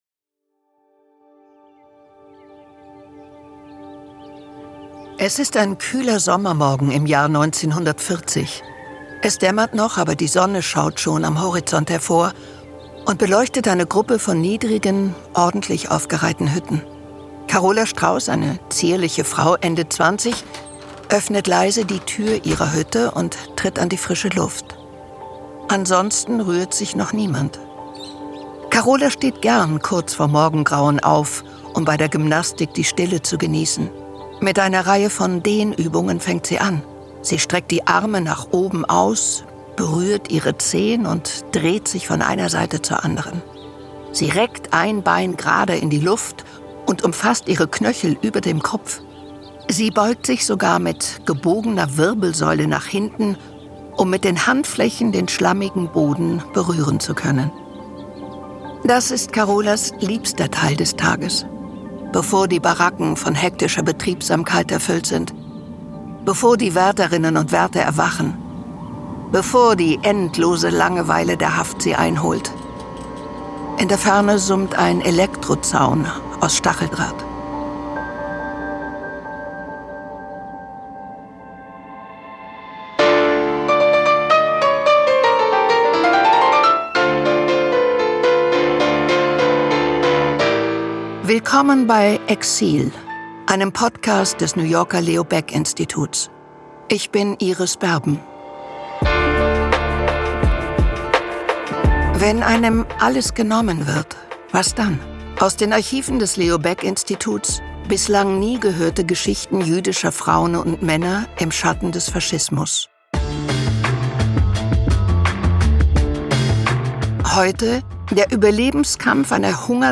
Sprecherin: Iris Berben